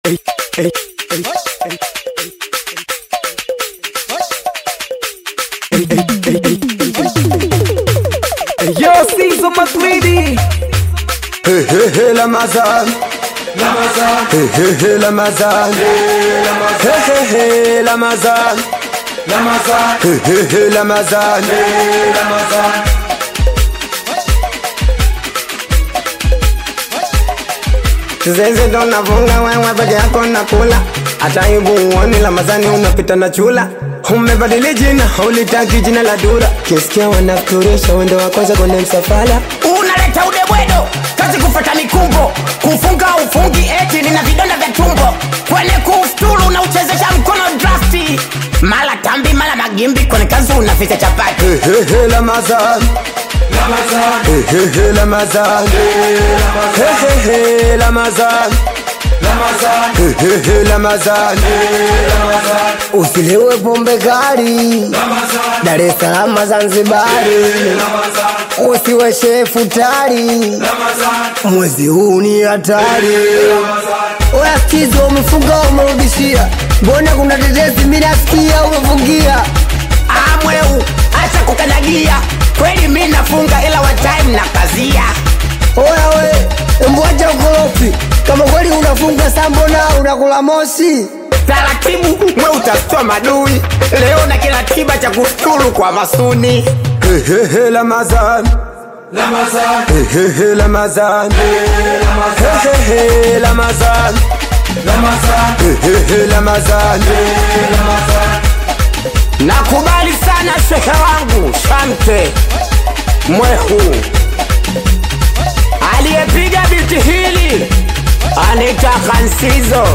atmospheric Afro‑Bongo/Singeli collaboration